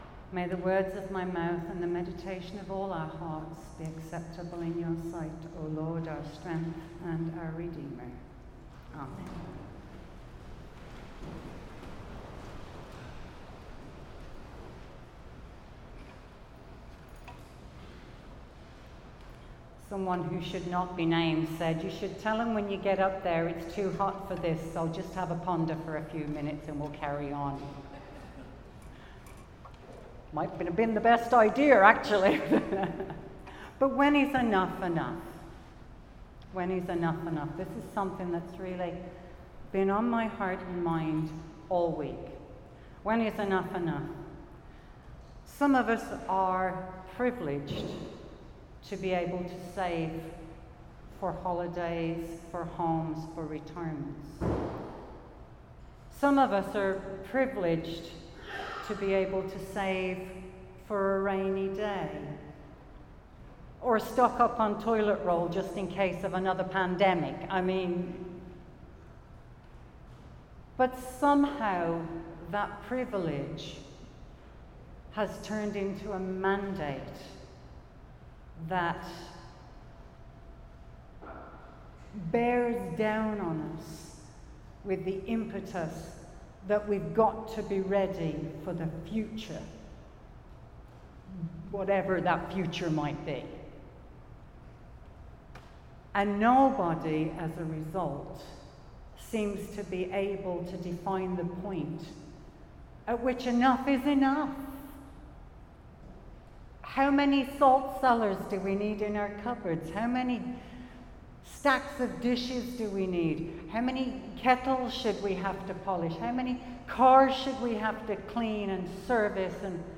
Sermons | Christ Church Cathedral Ottawa